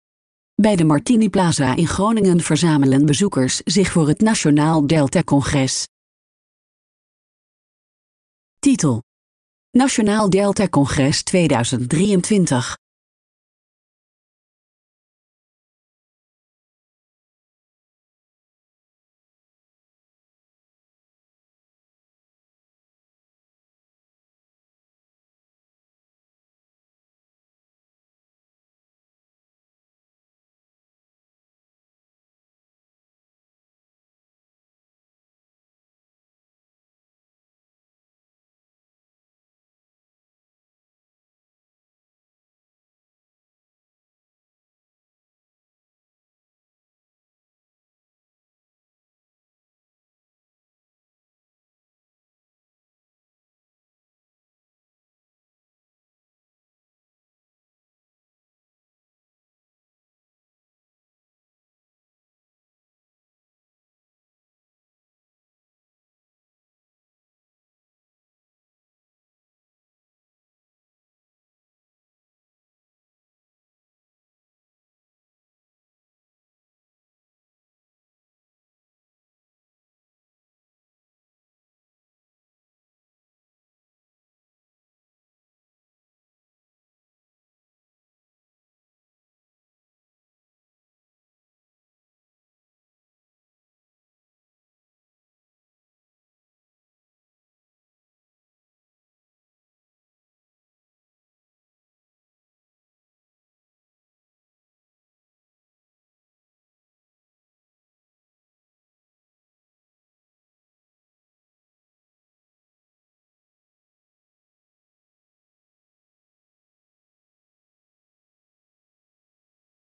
Deze video toont een registratie van het Nationaal Deltacongres 2023. Dit congres werd gehouden in het Martiniplaza in Groningen.
Diverse sprekers en mensen van de organisatie komen aan het woord, maar ook een aantal bezoekers reflecteert op wat de samenkomst voor hen betekent.
(ENERGIEKE MUZIEK)
Demissionair minister van Infrastructuur en Waterstaat, Mark Harbers, spreekt via een videoboodschap de zaal toe.
(APPLAUS)